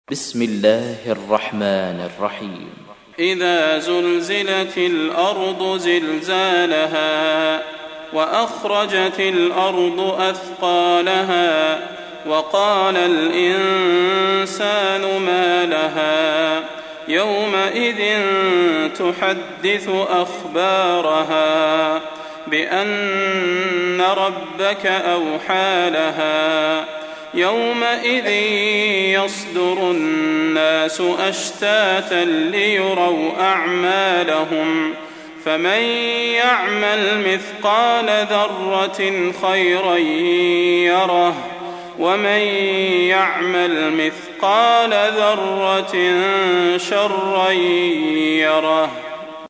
تحميل : 99. سورة الزلزلة / القارئ صلاح البدير / القرآن الكريم / موقع يا حسين